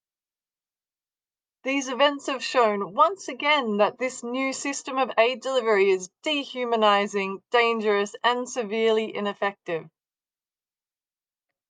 Voicenotes
recorded Monday 2nd June at Nasser Hospital, Gaza